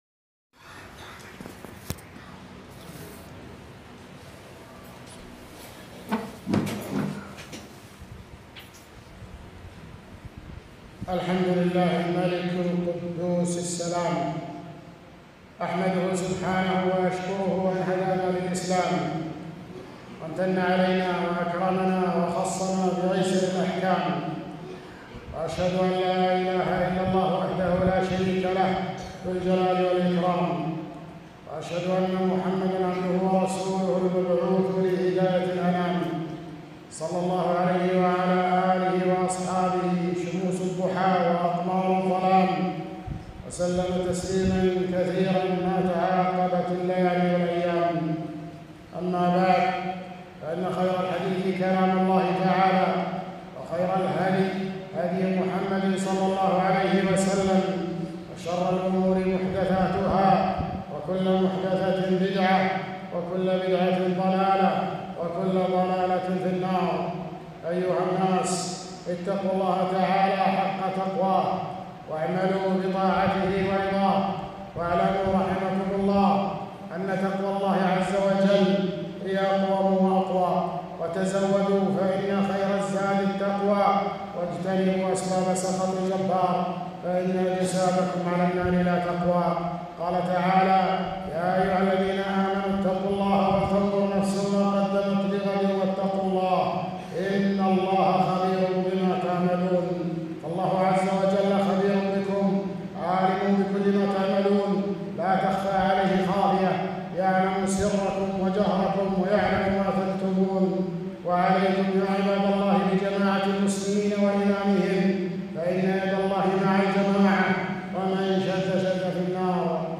خطبة - أحكام المسح على الخفين والجبيرة والخمار